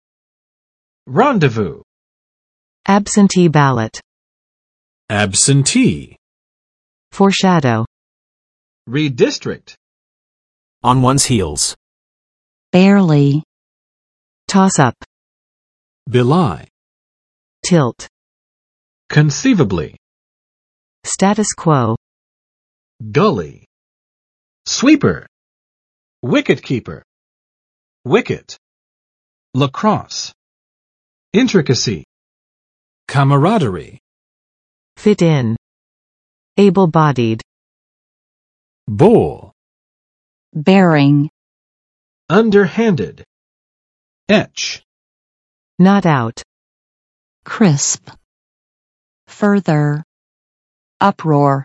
[ˋrɑndə͵vu] n. 约会